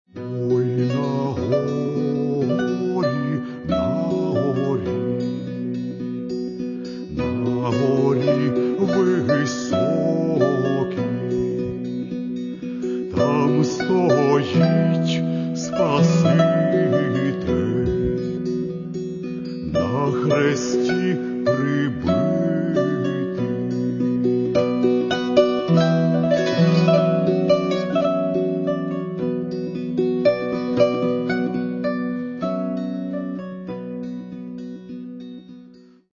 Каталог -> Народная -> Бандура, кобза
псальма) – у супроводі кобзи